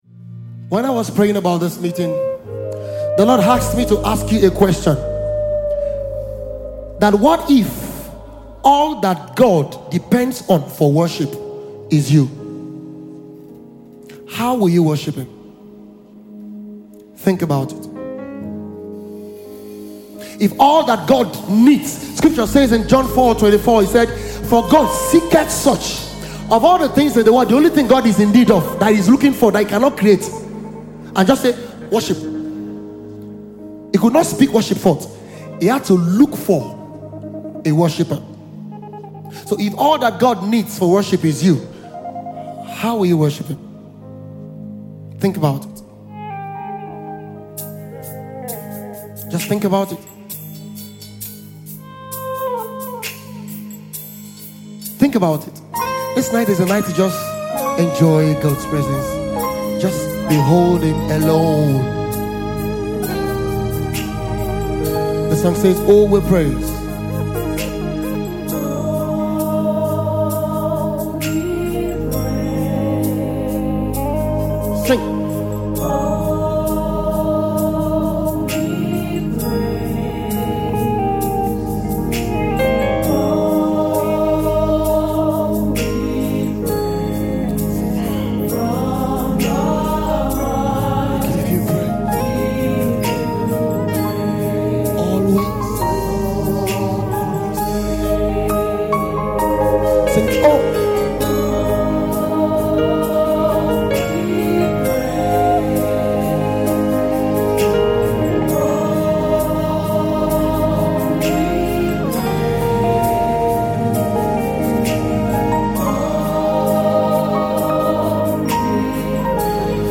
Nigeria-based Gospel Music Minister
a medley of sounds birthed from the place of intimacy